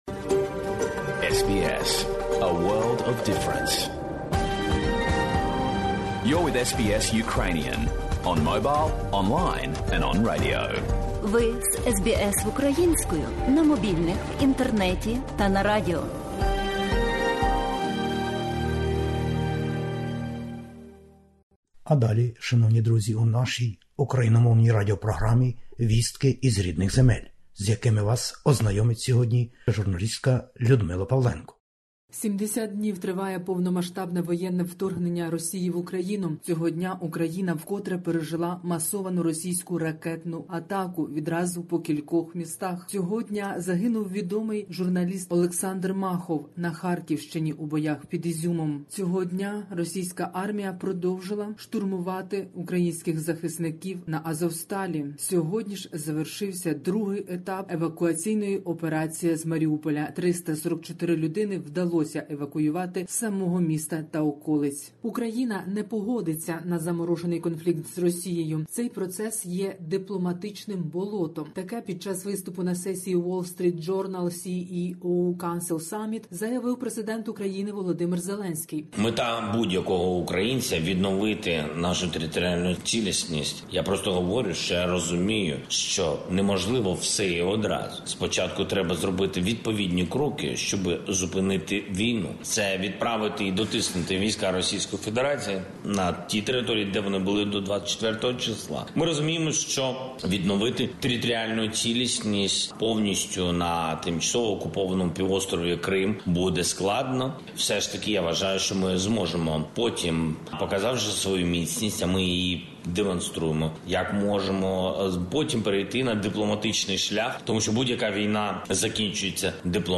Добірка новин про події на землях воюючої України. Ракетні атаки російських військ знову продовжувалися на Україну.